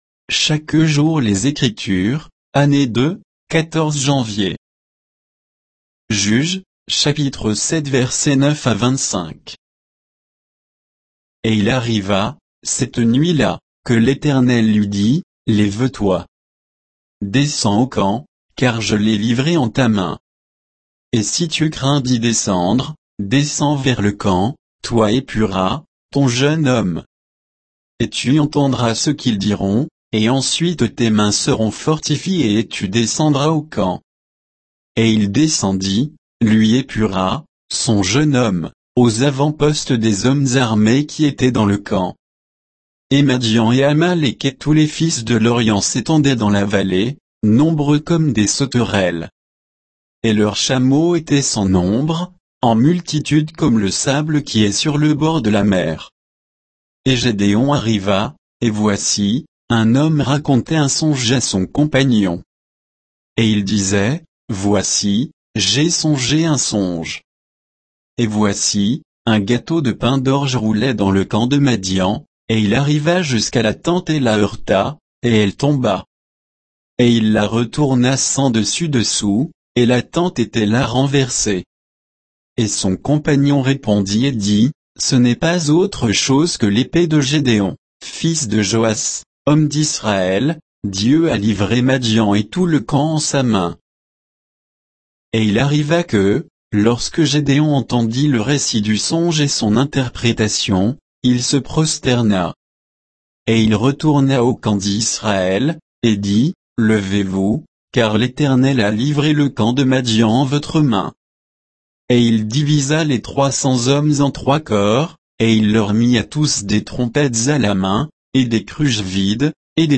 Méditation quoditienne de Chaque jour les Écritures sur Juges 7